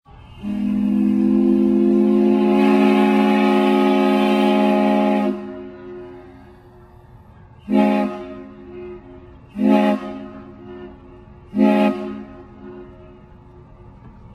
Hear the S.S. Nobska Whistle
whistle.mp3